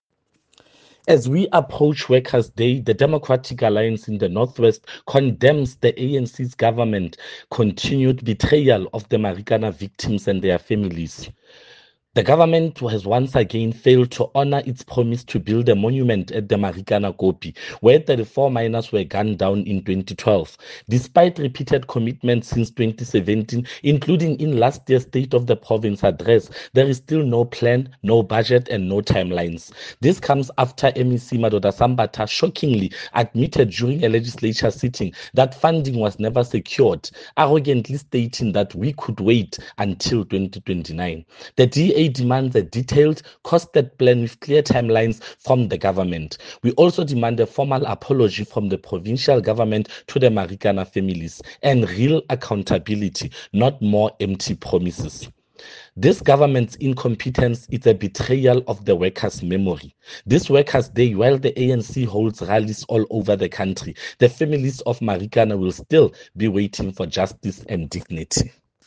Issued by Freddy sonakile – DA Caucus Leader in the North West Provincial Legislature
Note to Broadcasters: Please find attached soundbites in
Setswana by Freddy Sonakile